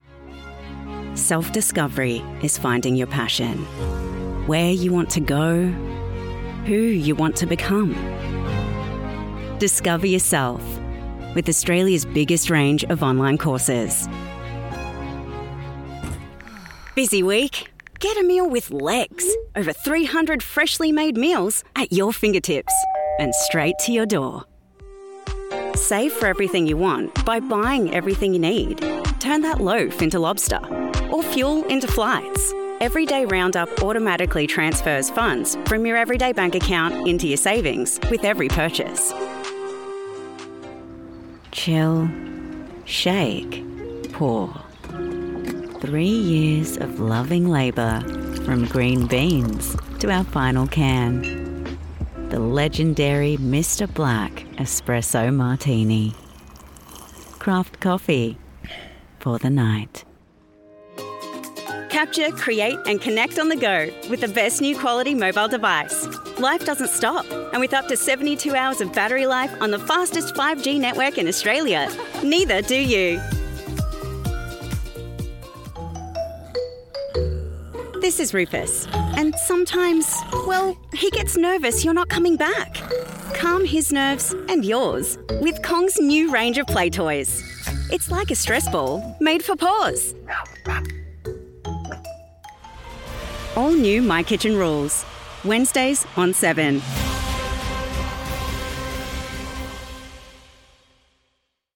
Anglais (Australien)
De la conversation
Amical
Menaçant